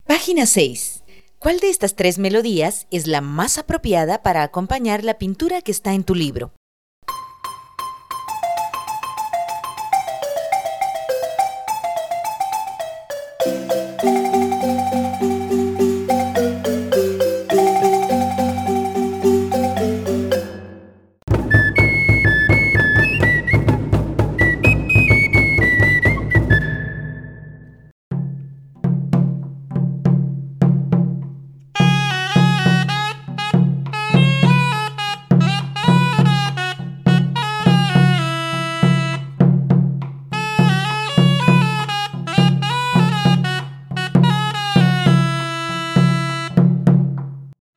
Melodías tradicionales (Frgs.)